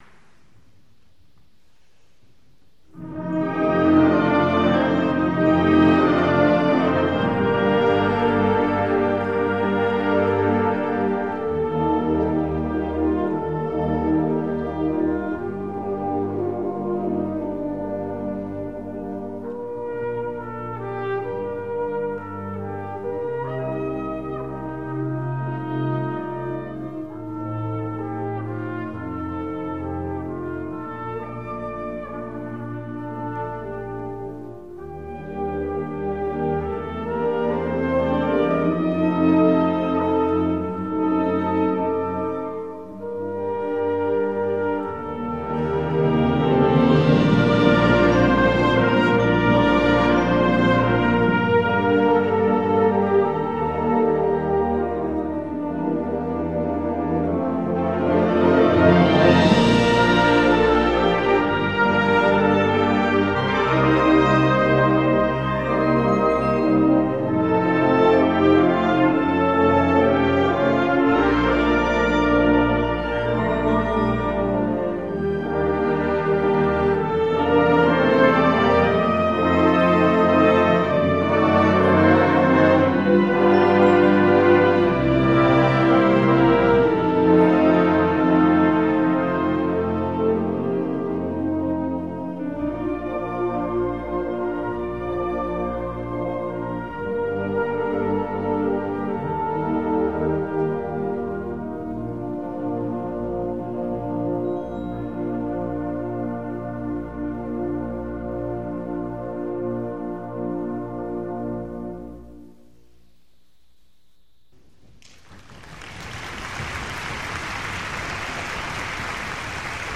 インストゥルメンタル（吹奏楽）版
B-flat Major